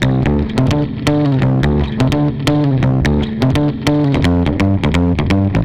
AC_GuitarB_85-D2.wav